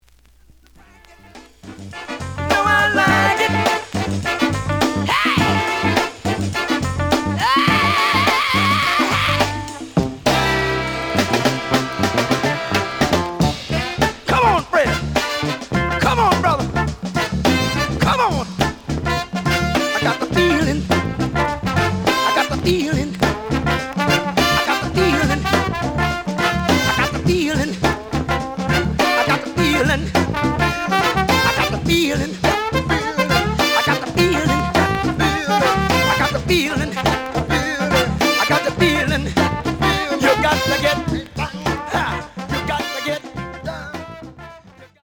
The audio sample is recorded from the actual item.
●Genre: Funk, 70's Funk